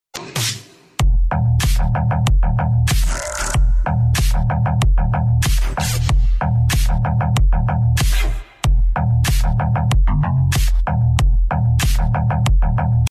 Laser Light Sound Effects Free Download